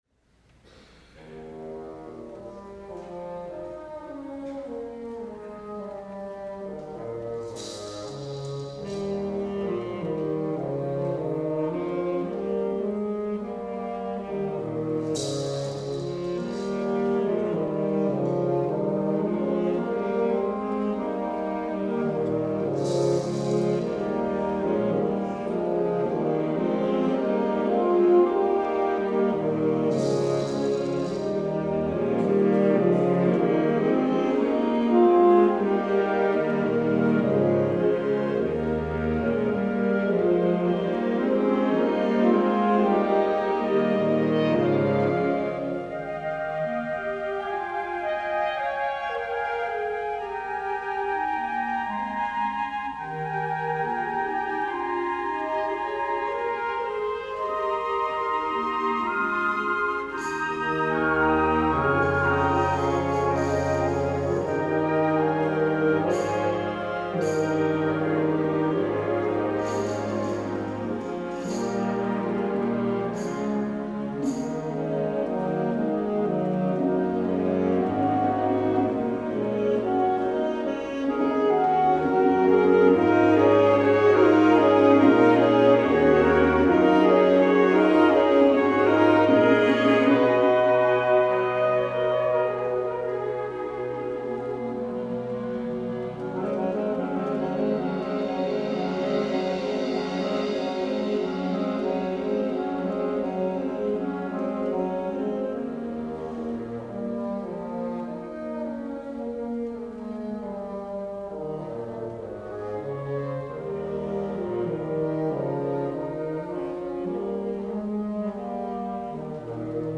for Band (1989)